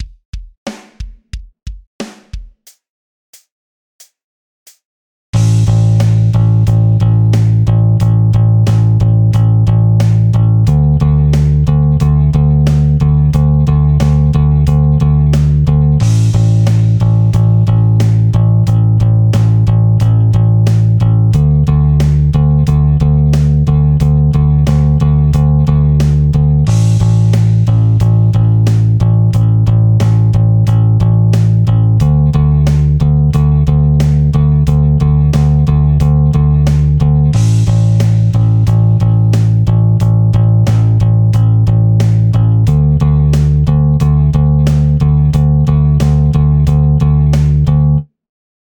5．開放弦とタイを使用したベース練習フレーズ7選！
3．複数のタイが含まれる練習フレーズ